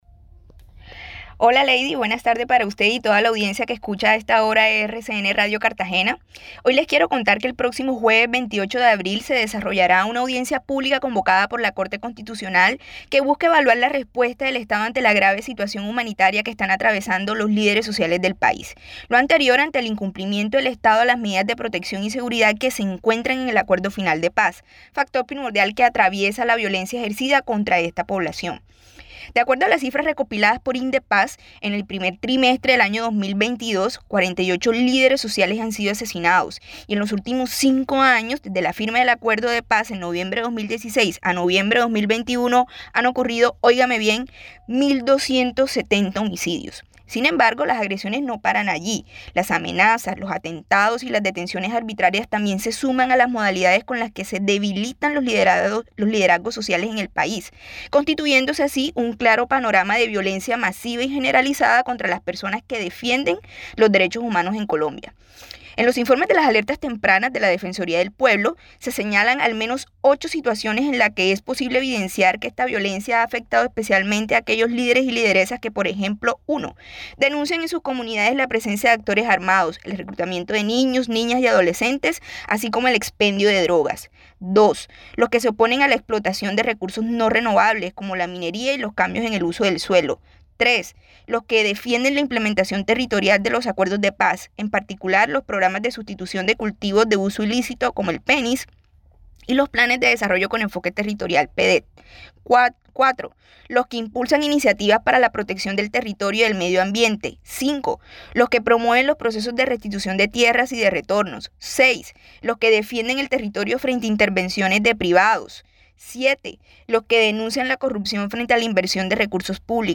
Esta columna fue publicada en RCN Radio Cartagena.